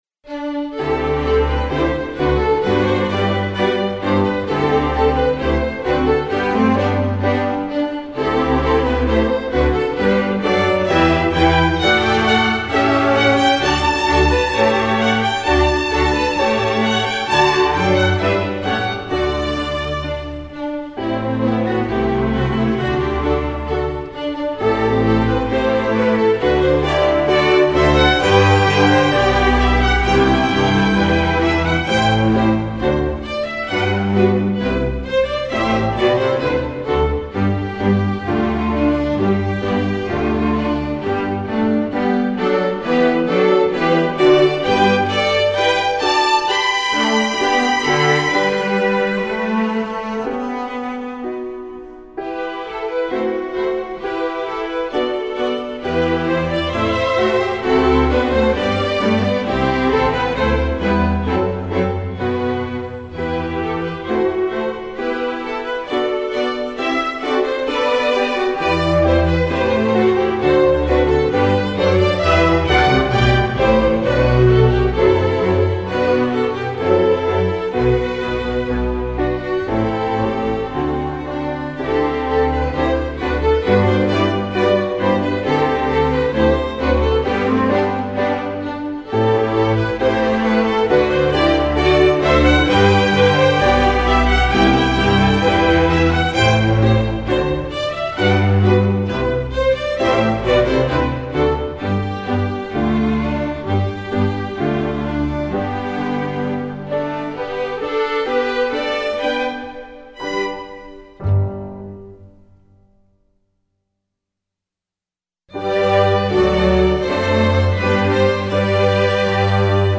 Instrumentation: string orchestra